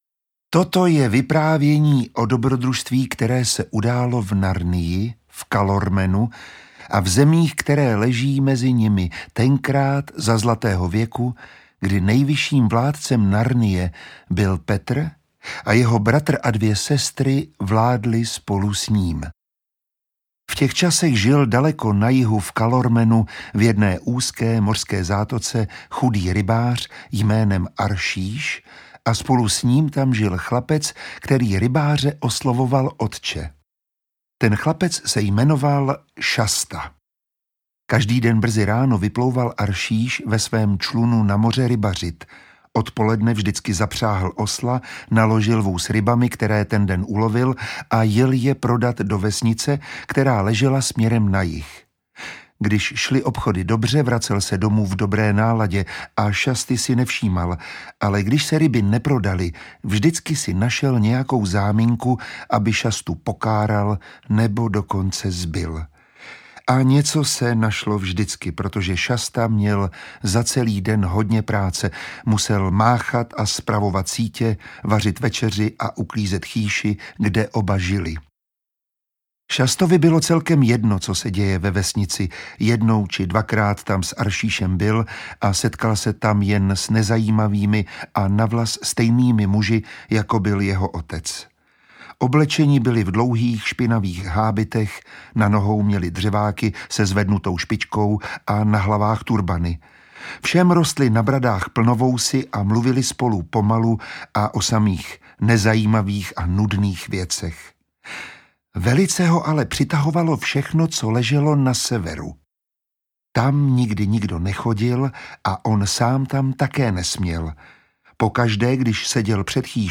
Ukázka z knihy
Dokáže odvrátit nebezpečí, které Narnii hrozí?Nechte se i vy pohltit napínavým příběhem s nečekanými zvraty a zaposlouchejte se do charismatického hlasu Miroslava Táborského, díky němuž před vámi všechny fantastické postavy doslova ožijí.
• InterpretMiroslav Táborský
letopisy-narnie-3-kun-a-jeho-chlapec-audiokniha